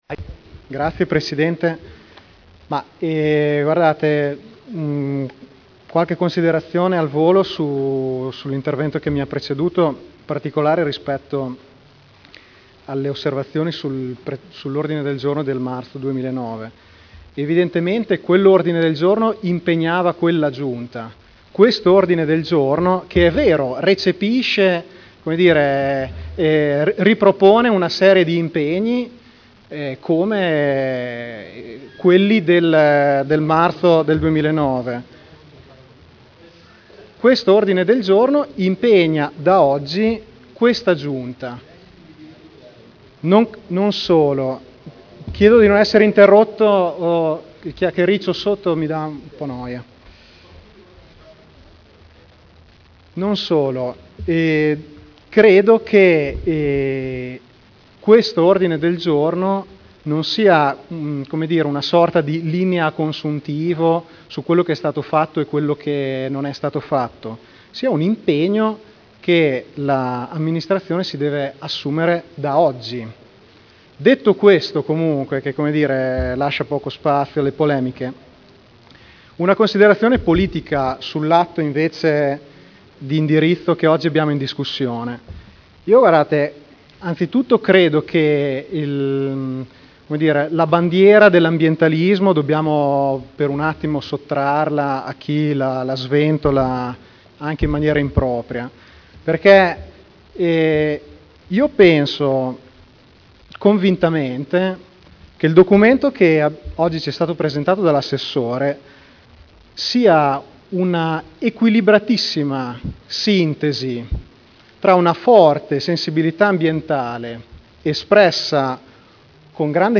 Dibattito. Delibera: Approvazione dell’atto di indirizzo per l’attuazione del Piano delle Attività Estrattive del Comune di Modena (Commissione consiliare del 28 giugno 2011)